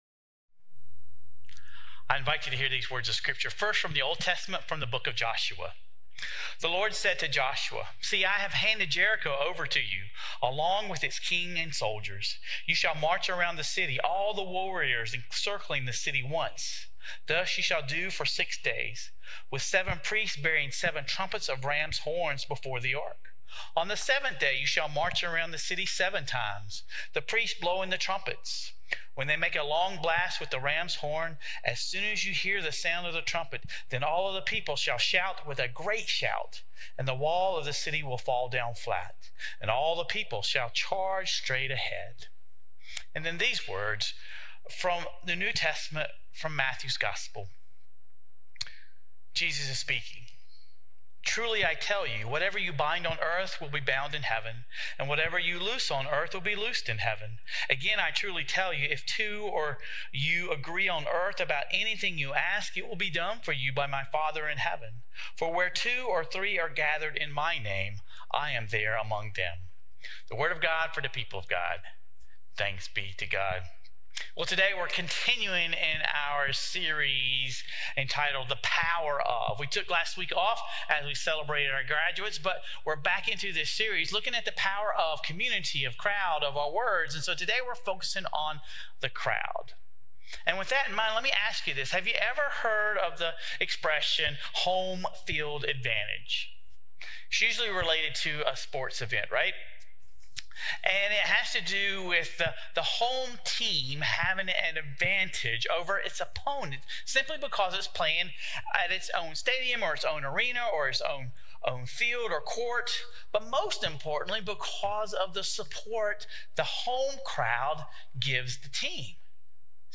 There is power in the crowd. Sermon Reflections : There are positive and negative aspects to crowds.